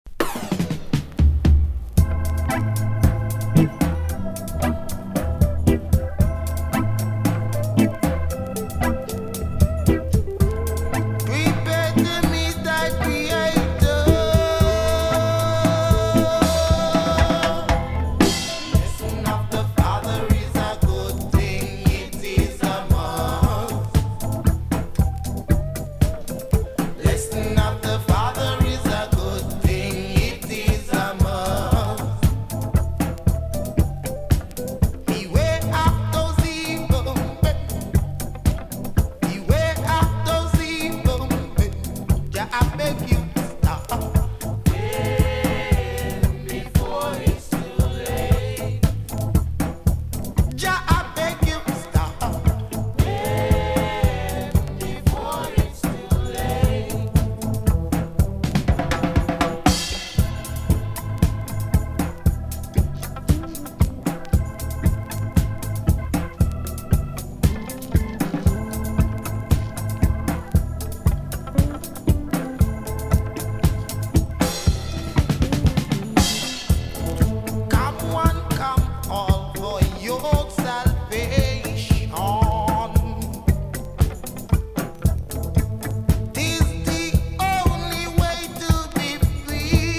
気持ち良い爽やかなイントロから徐々にダブワイズしていく展開がとにかく最高!!